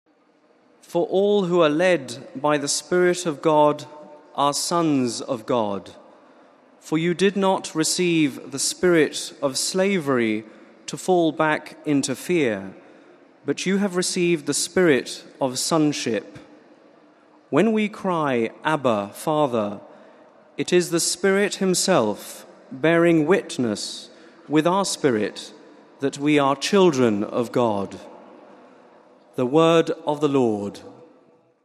The general audience of May 8th was held in the open, in Rome’s St. Peter’s Square. It began with several aides reading a passage from the Letter of St. Paul to the Romans in several languages.